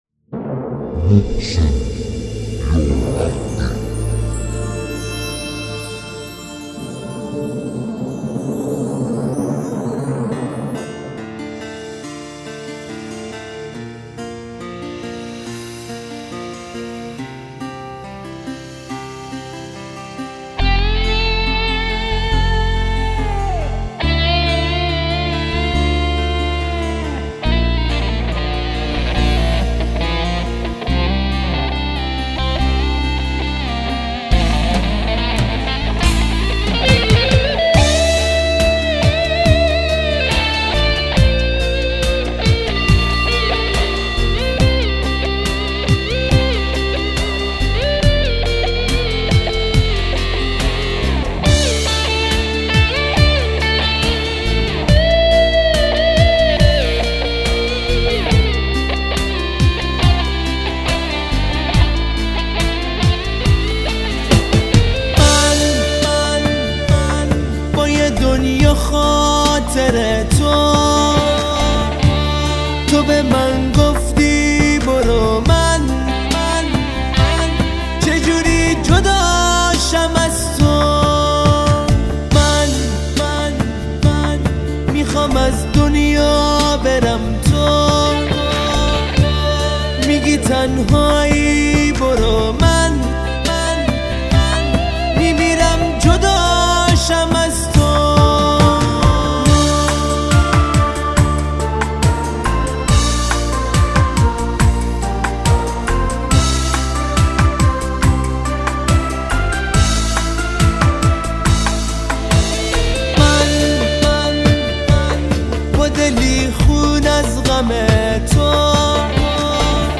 تك آهنگ ایرانی
آهنگ احساسی و فوق العاده زیبای